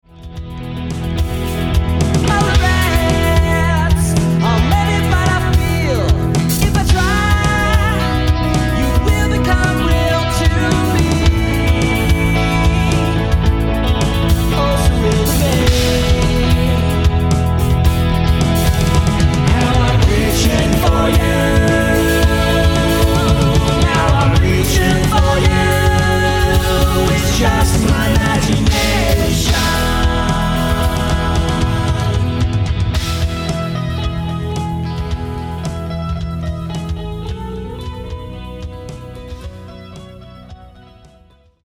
The :40 sec teaser we used to promote the single
lead vocals